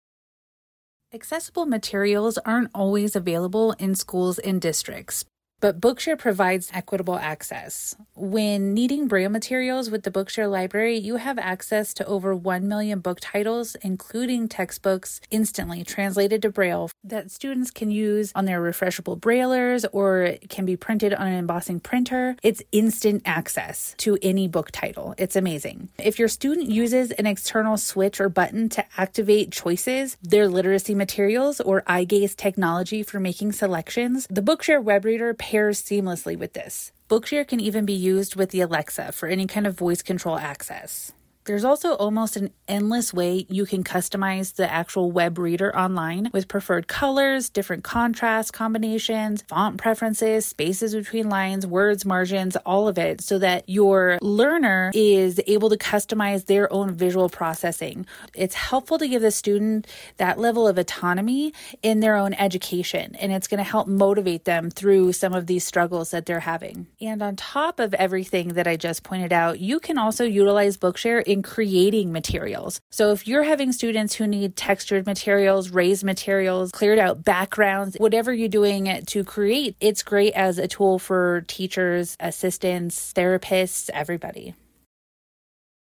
Teacher, Bookshare Champion